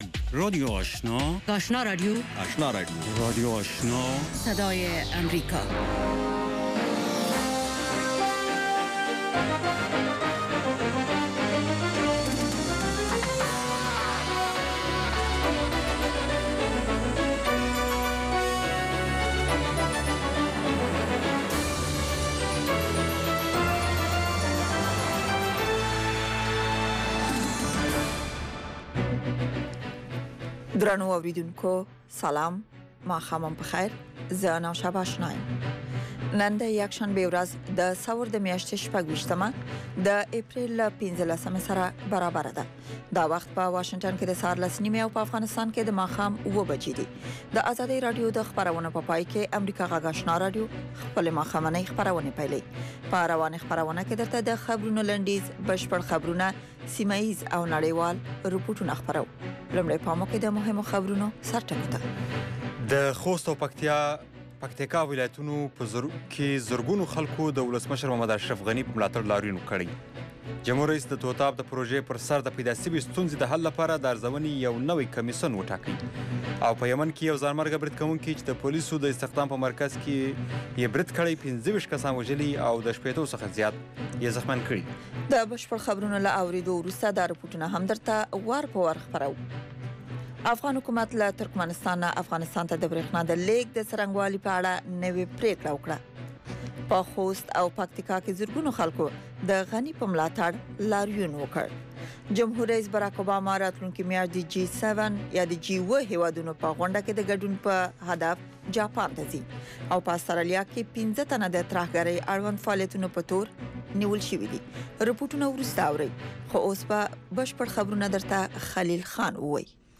ماښامنۍ خبري خپرونه
په دې نیم ساعته خپرونه کې د افغانستان او نړۍ تازه خبرونه، مهم رپوټونه، مطبوعاتو ته کتنه او مرکې شاملې دي.